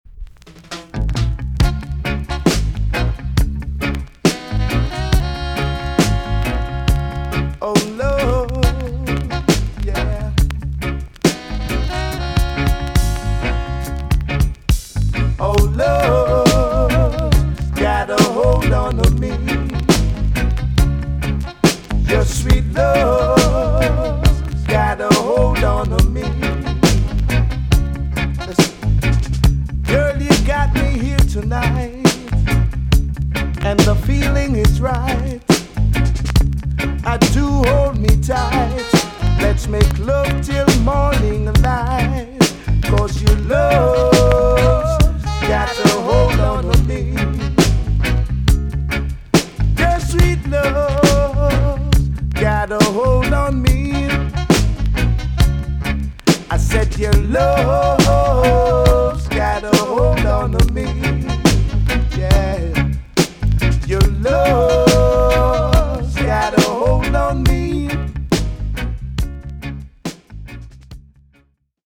TOP >REGGAE & ROOTS
EX-~VG+ 少し軽いチリノイズがありますが良好です。